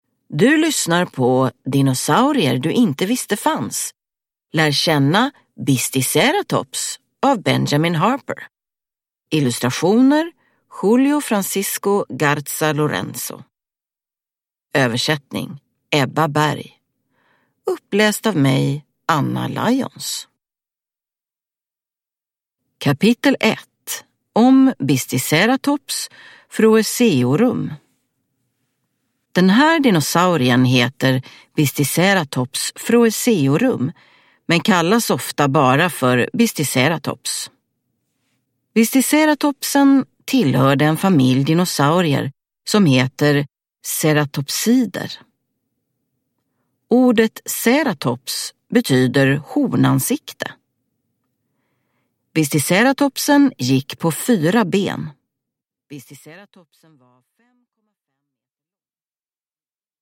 Lär känna Bisticeratops – Ljudbok